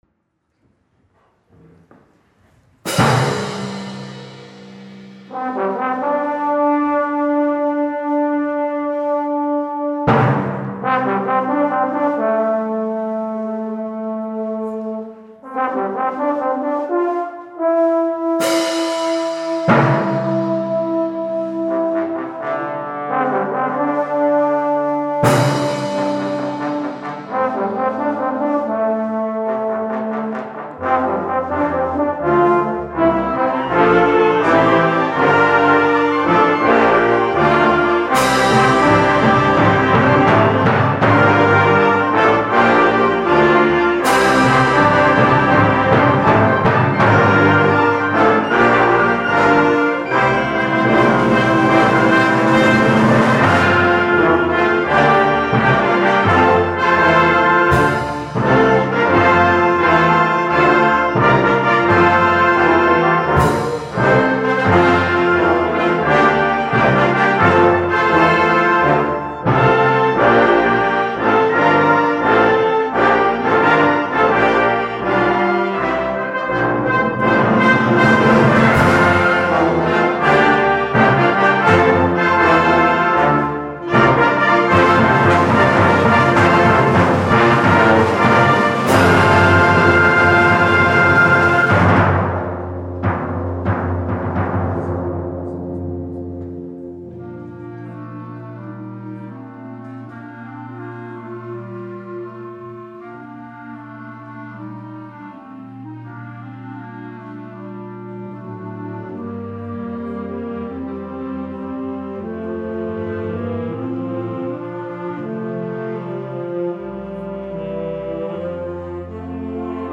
Konzert 2016